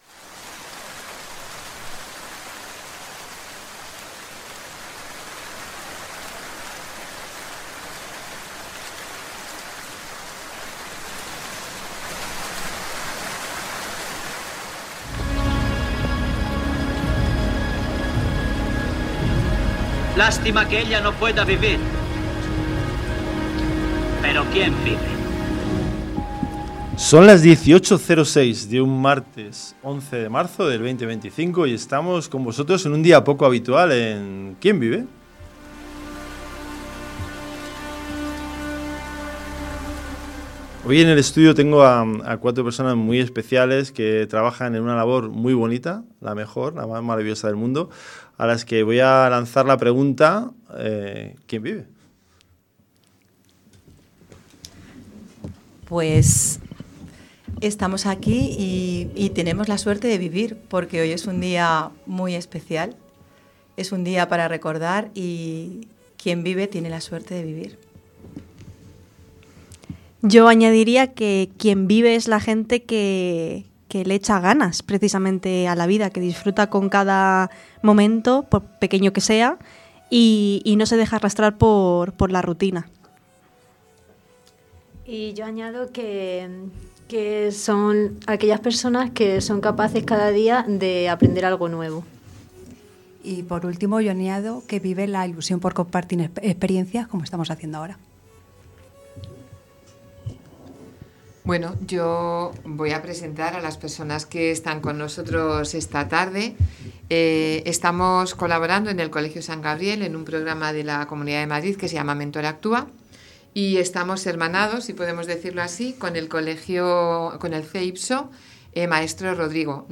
En este episodio especial, nos reunimos en un encuentro muy especial dentro del programa MentorActúa, promovido por el ISMIE. Un espacio de aprendizaje y colaboración donde educadores comparten experiencias, reflexionan sobre su labor y siguen creciendo juntos.